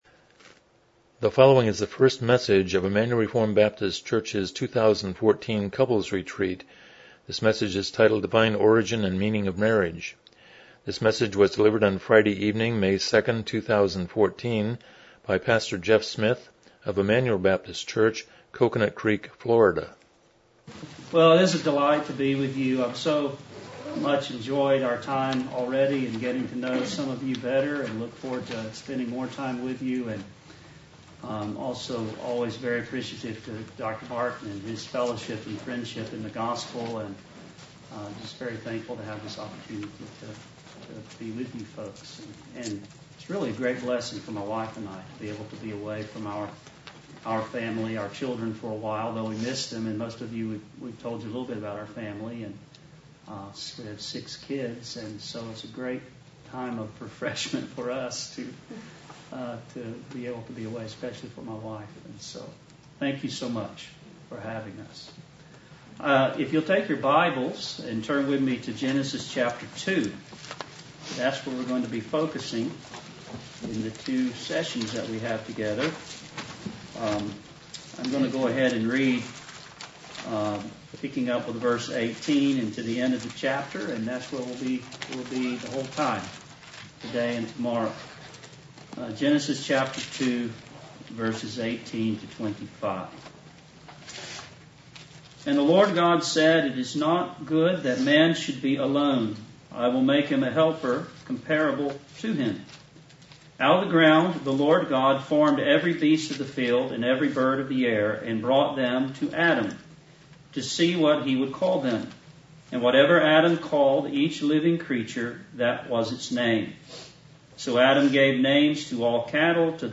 Miscellaneous Service Type: Special Event « 53 The Greatest Beatitude #1